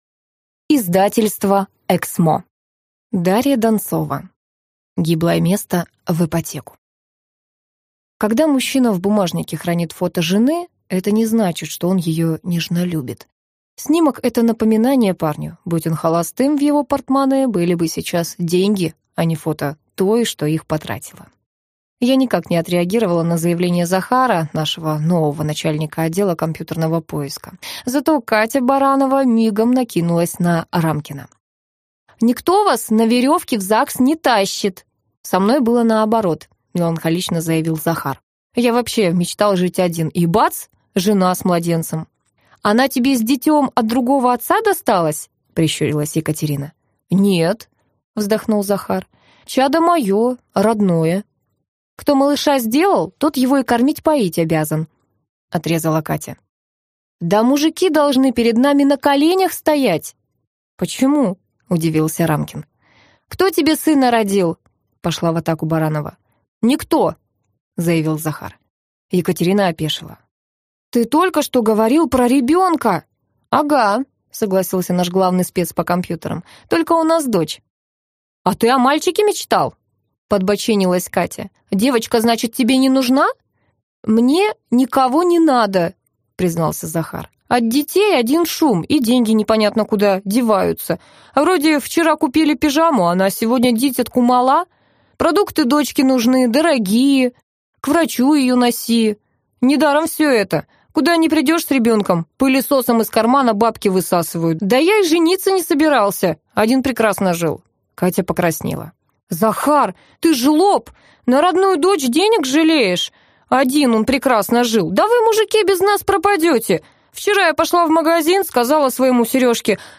Аудиокнига Гиблое место в ипотеку | Библиотека аудиокниг